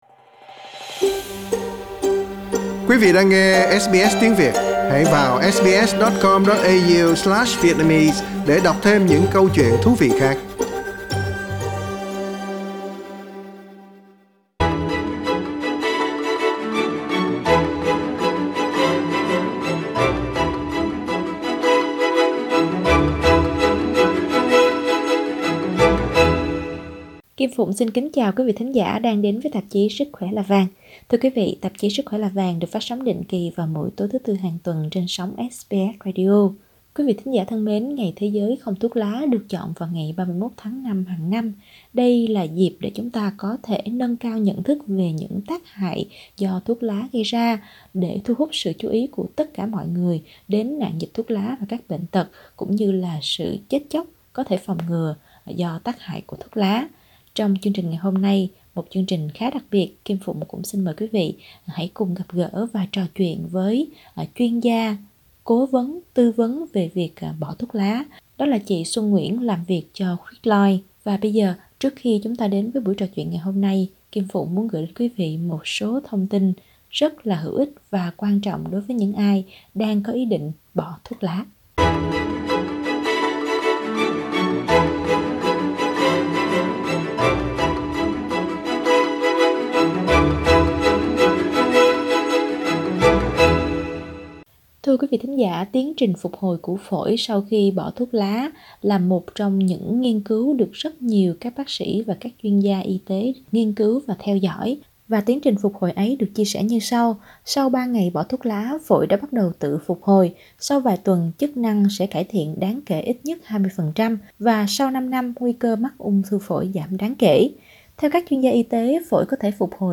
Để nghe toàn bộ nội dung buổi trò chuyện, mời quý vị nhấn vào phần audio.